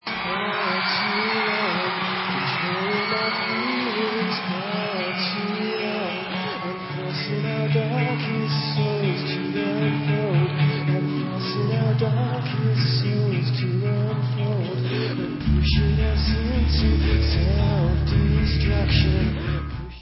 Live From Le Zenith